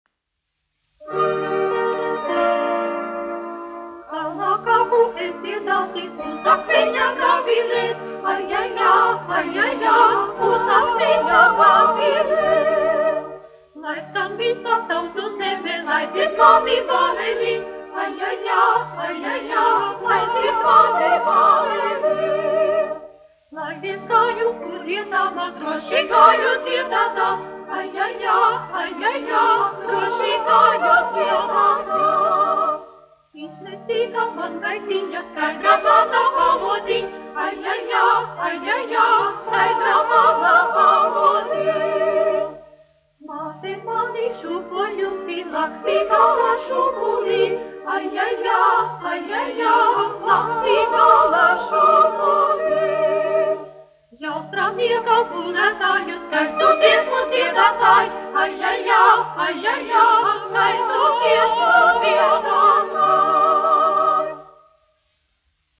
Kalnā kāpu es dziedāti : latviešu tautas dziesma
Latvijas Radio koklētāju ansamblis, izpildītājs
Latvijas Radio sieviešu vokālais sekstets, izpildītājs
1 skpl. : analogs, 78 apgr/min, mono ; 25 cm
Latviešu tautasdziesmas